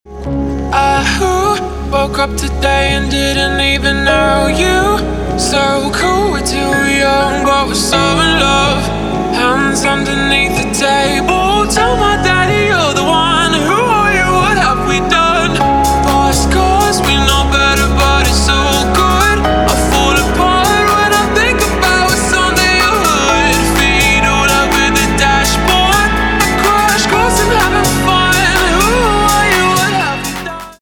• Качество: 256, Stereo
dance
EDM
Melodic
romantic
vocal